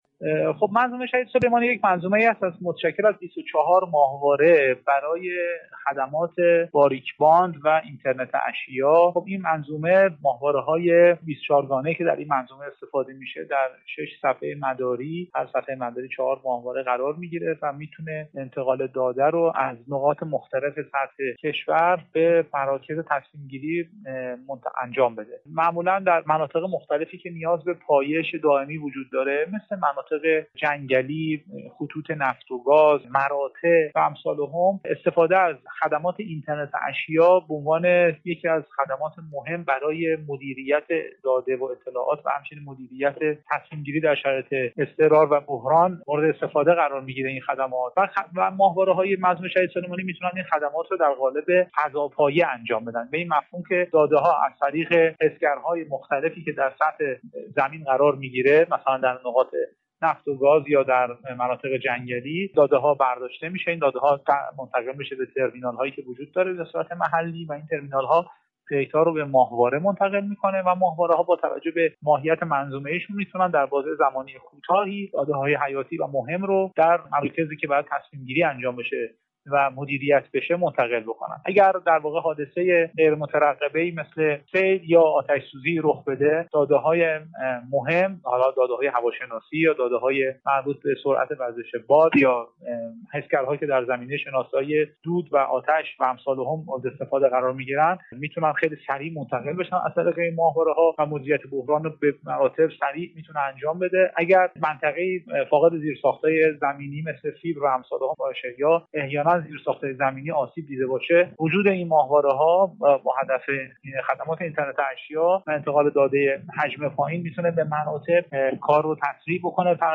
جهان سیاست در برنامه امروز و در بخش دریچه، به مناسبت روز فناوری فضایی به واكاوی دستاوردهای این صنعت پرداخت و در این خصوص با آقای حسن سالاریه، رئیس سازمان فضایی ایران گفتگو كرد.